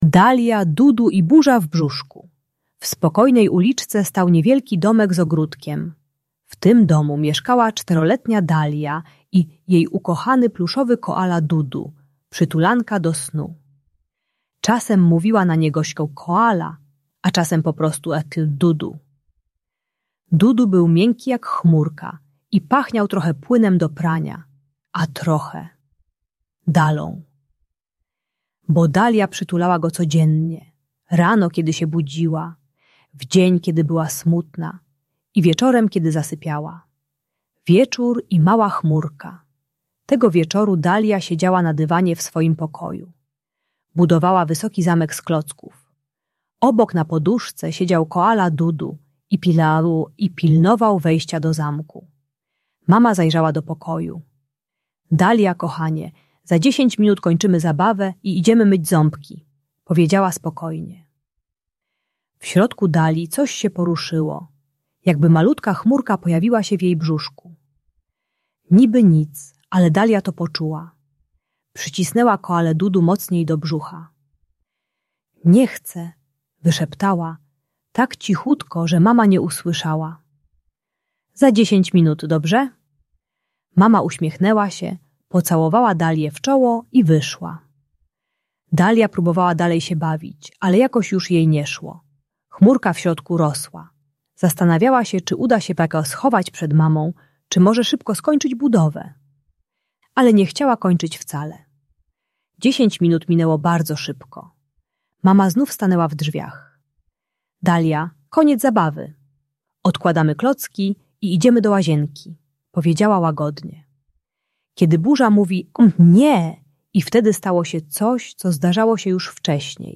Dalia, Dudu i burza w brzuszku - Agresja do rodziców | Audiobajka